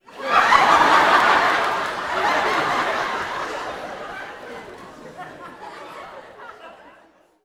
Increased volume of laugh tracks again
Audience Laughing-08.wav